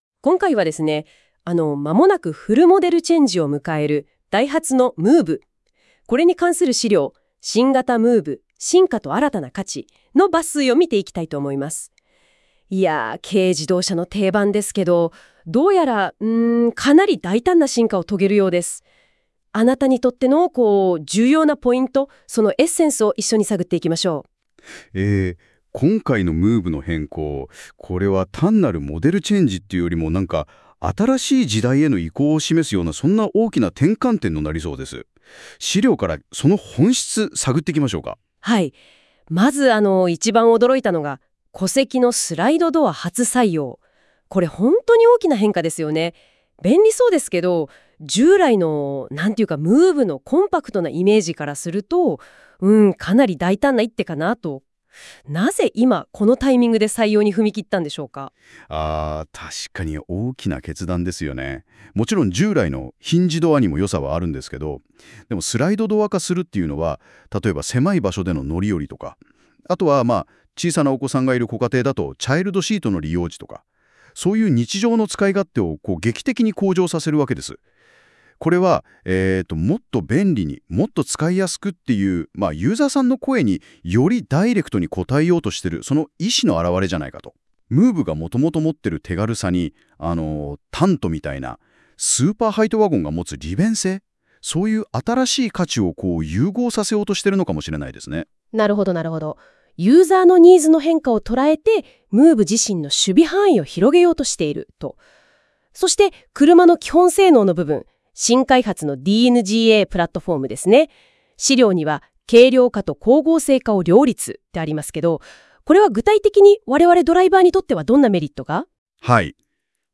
記事をまとめたAIラジオはこちら↓